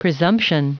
Prononciation du mot presumption en anglais (fichier audio)
Prononciation du mot : presumption